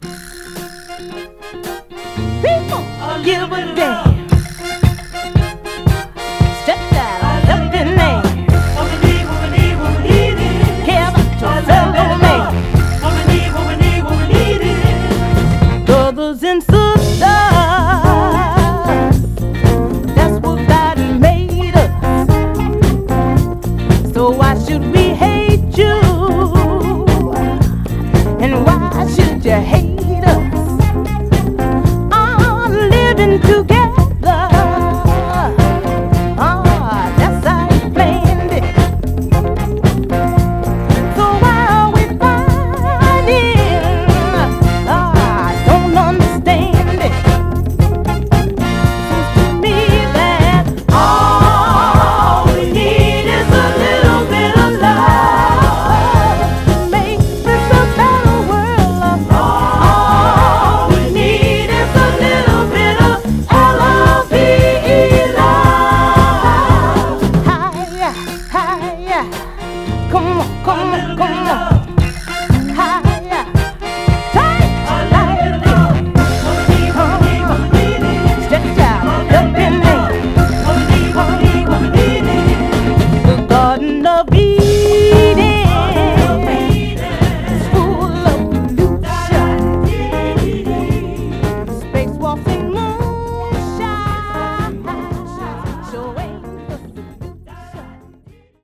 フィリー発のソウル・グループ
※試聴音源は実際にお送りする商品から録音したものです※